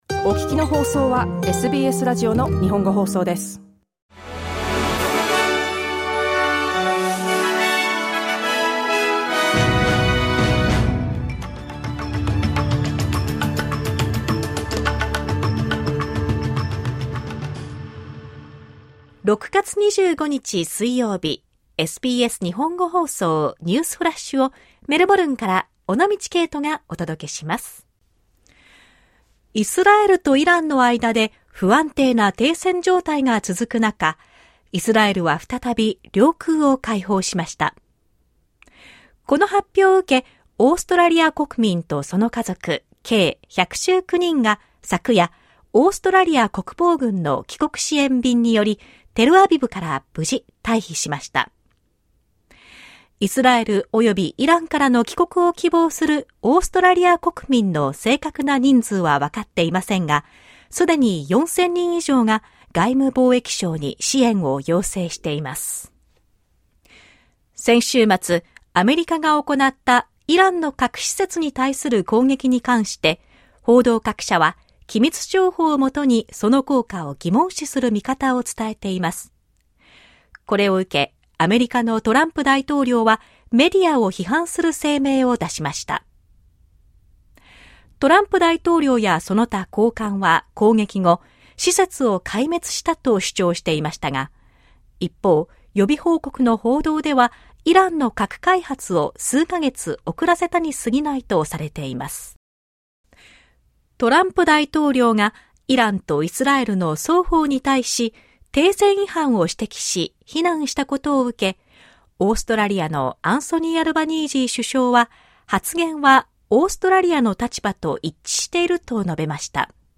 SBS日本語放送ニュースフラッシュ 6月25日 水曜日